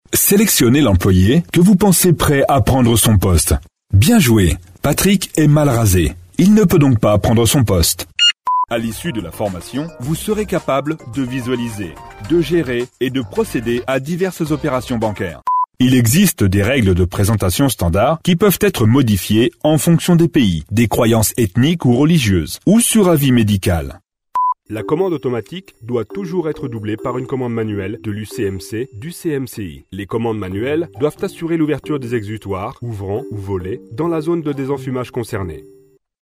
Comédien voix off pour radios, webradios, productions ciné ou tv.
Sprechprobe: eLearning (Muttersprache):
French voice over for radios, webradios and tv or movies productions.